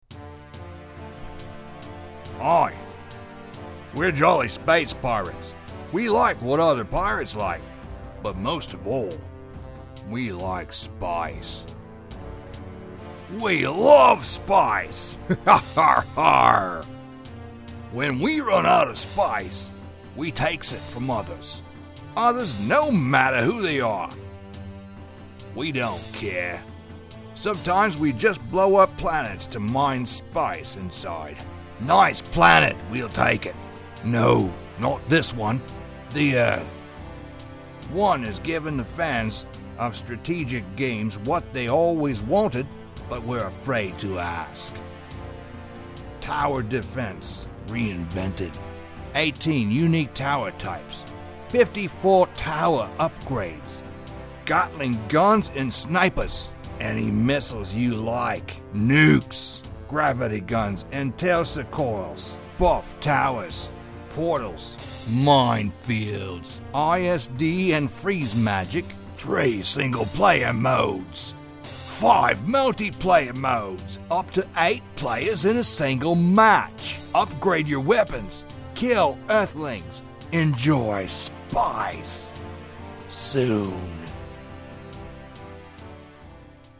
Демо игровой Категория: Аудио/видео монтаж